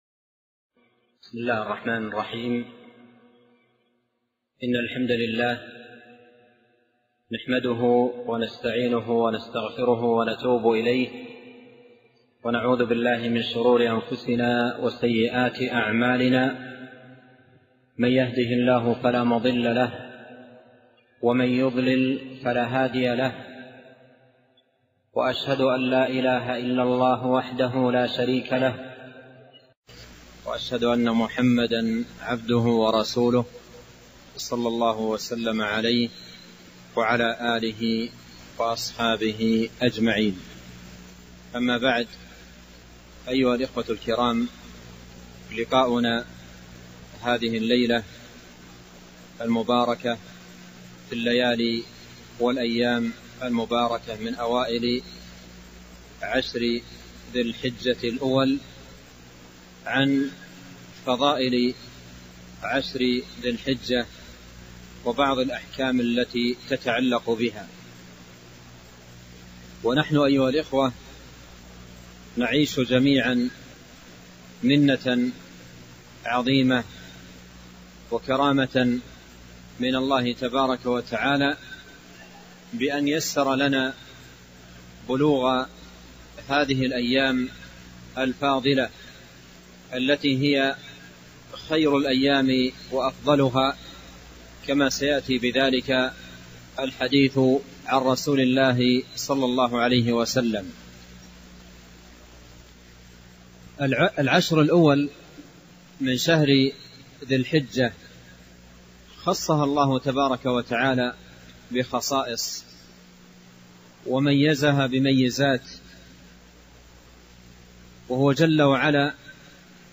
محاضرة - فضل عشر ذي الحجة - دروس الكويت